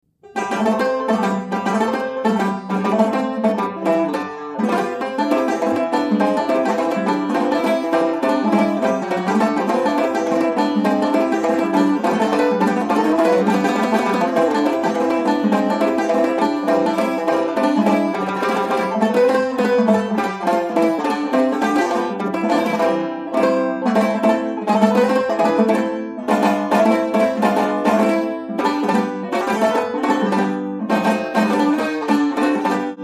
NICKELODEONS and ORCHESTRIONS